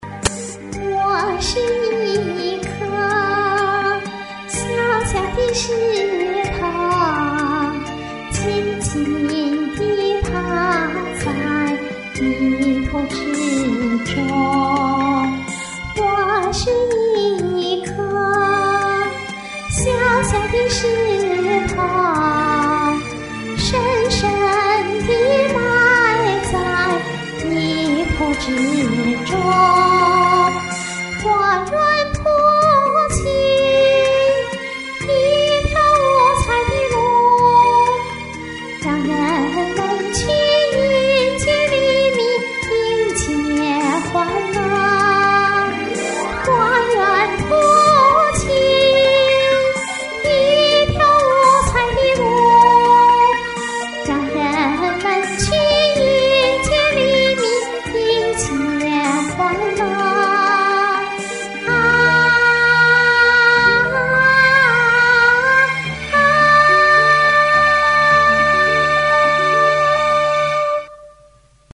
又重录了一遍，稍微好一些。
最后一句，我属于功底不够，怎么也唱不好。